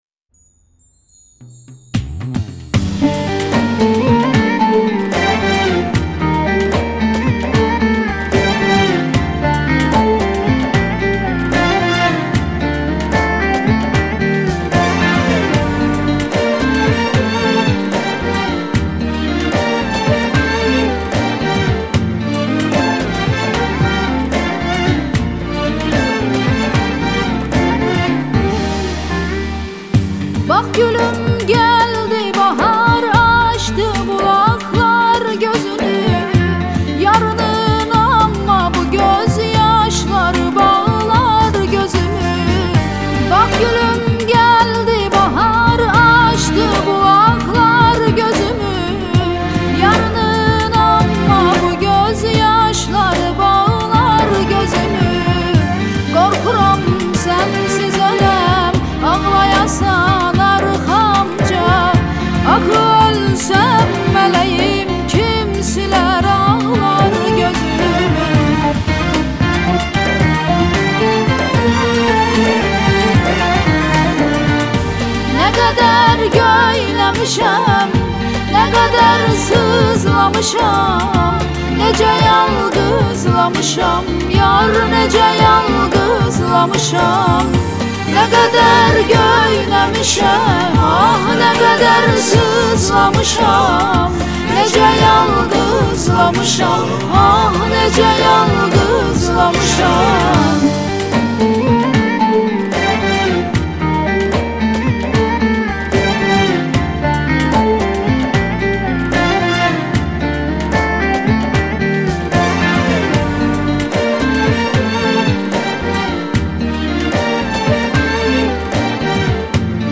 آهنگ ترکی
با صدای زن